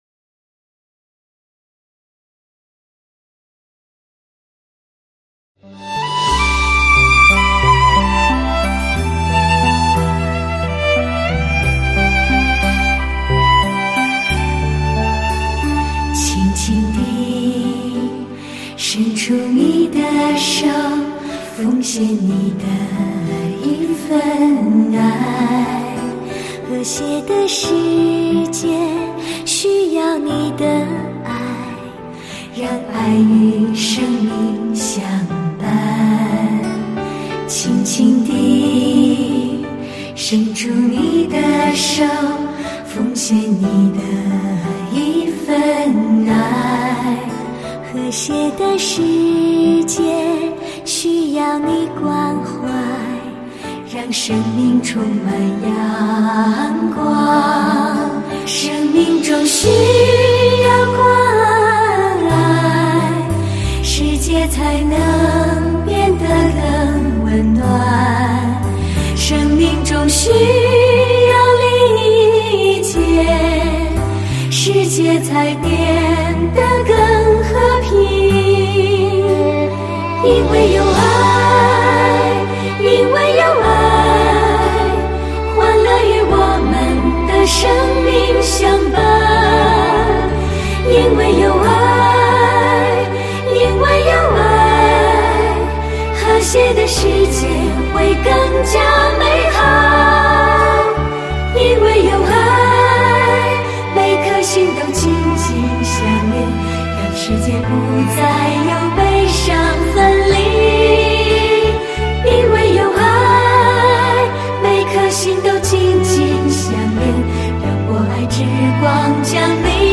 英国伦敦“世界宗教联合大会”